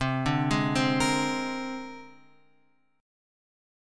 C 7th Suspended 2nd Sharp 5th